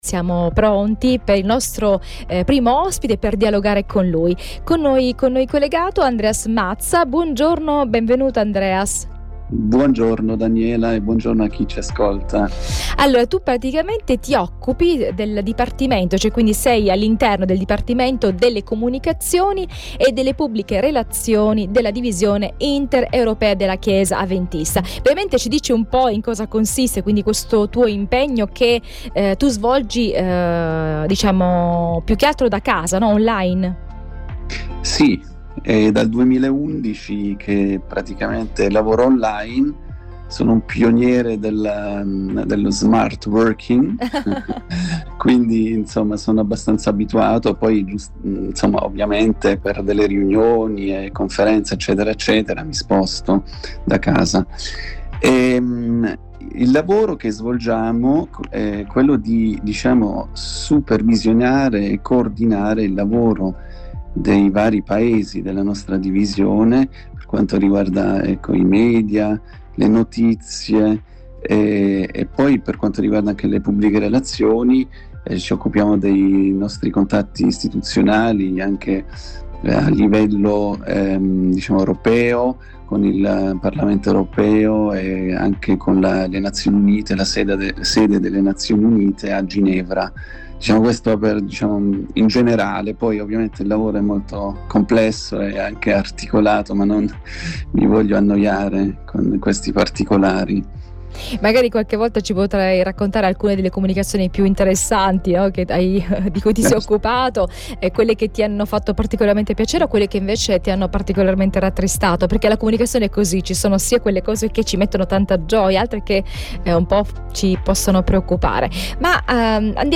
Riflessione sul salmo 23